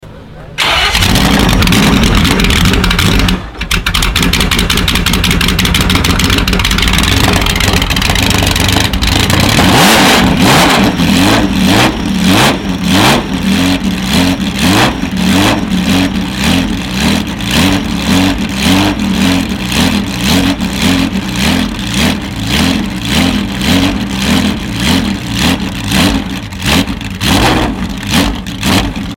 Amazing Ferrari 512 BB LM! sound effects free download